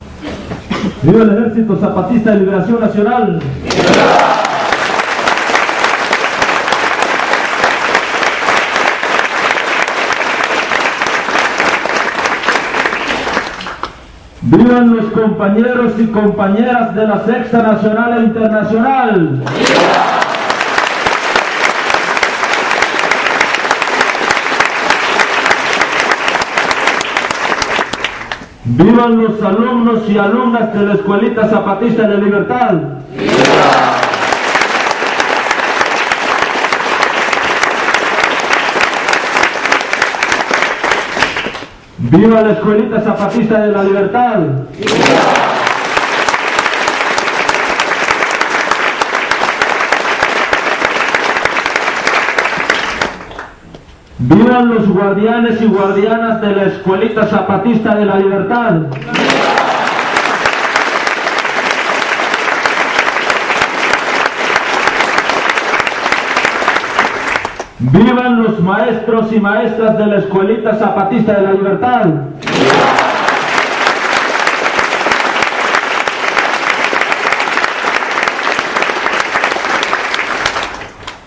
01 Vivas de los zapatistas.mp3